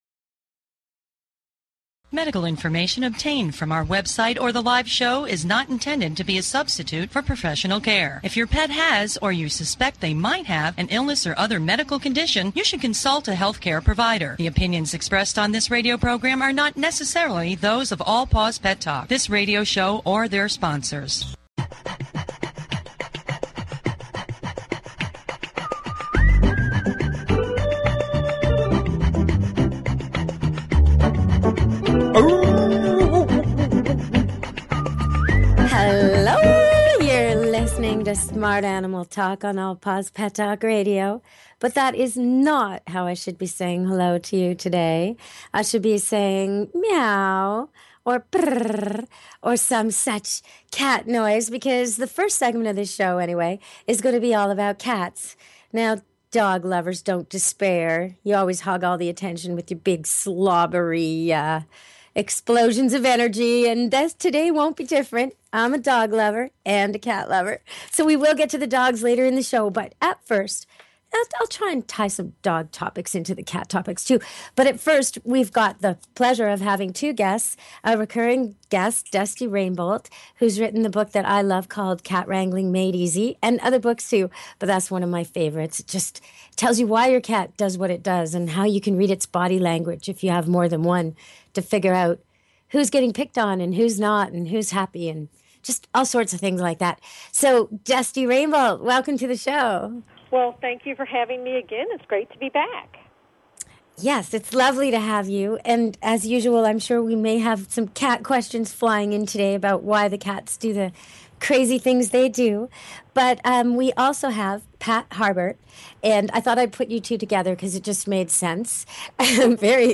Talk Show Episode, Audio Podcast, ET-First Contact Radio and with guest on , show guests , about , categorized as Variety